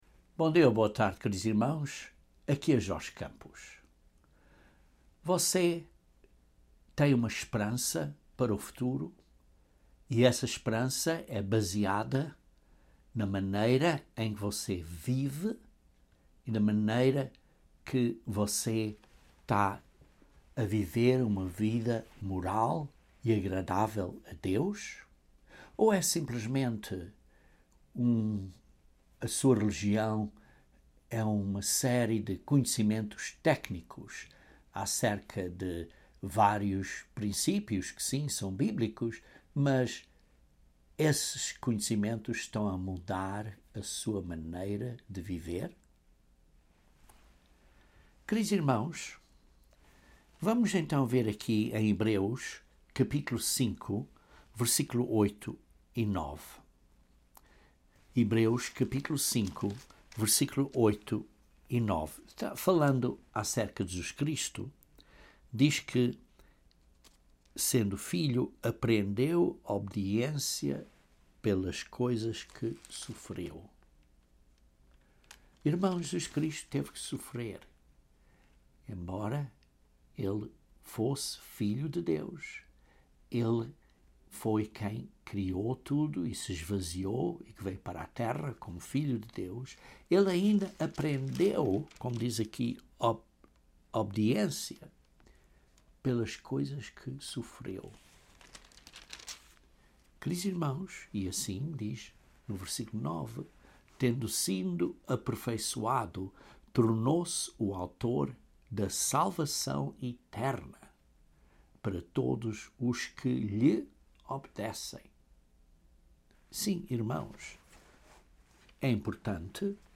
Hebrews 6:1-2 identifica seis doutrinas básicas. Este sermão aborda duas dessas doutrinas básicas.